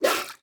Minecraft Version Minecraft Version snapshot Latest Release | Latest Snapshot snapshot / assets / minecraft / sounds / mob / dolphin / blowhole2.ogg Compare With Compare With Latest Release | Latest Snapshot
blowhole2.ogg